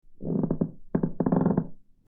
Floorboards Creaking 02
Floorboards_creaking_02.mp3